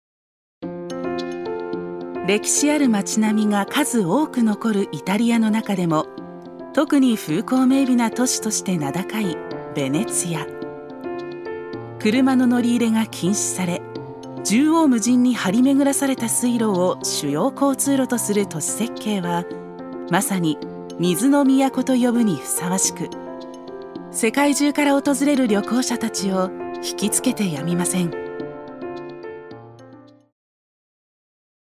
ナレーション１